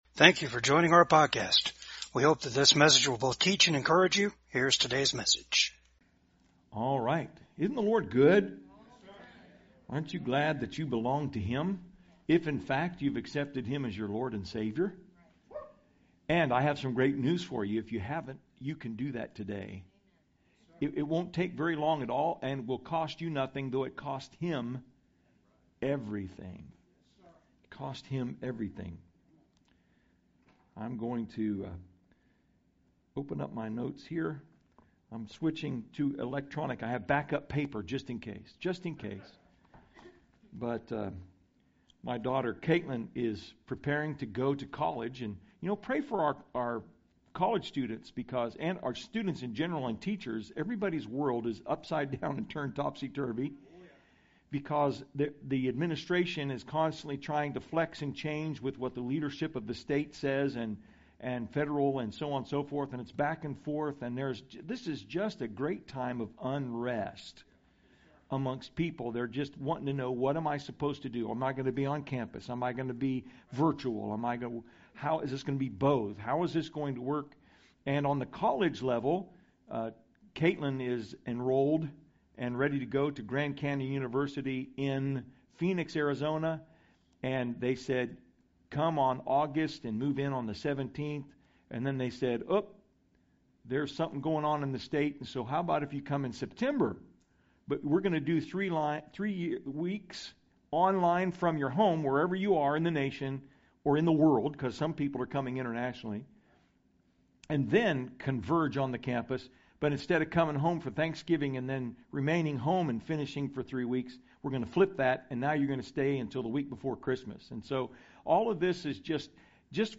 Passage: Psalms 23 Service Type: VCAG SUNDAY SERVICE